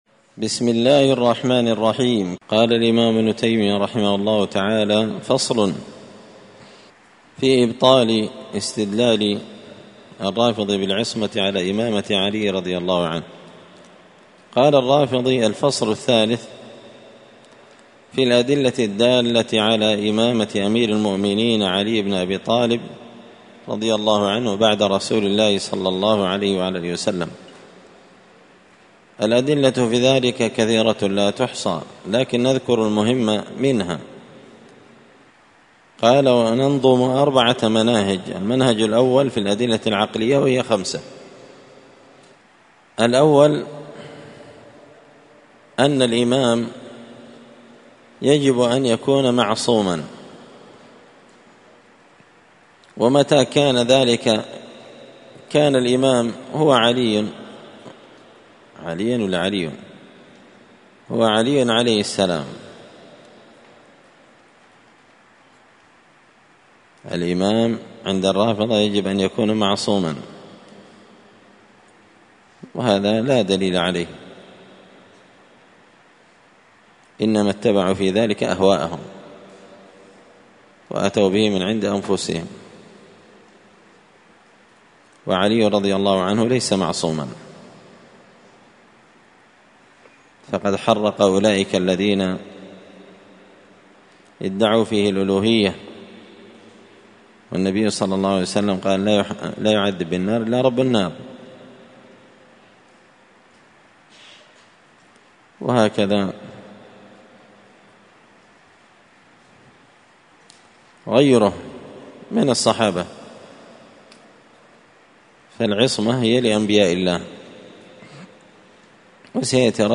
الأربعاء 8 محرم 1445 هــــ | الدروس، دروس الردود، مختصر منهاج السنة النبوية لشيخ الإسلام ابن تيمية | شارك بتعليقك | 8 المشاهدات
مسجد الفرقان قشن_المهرة_اليمن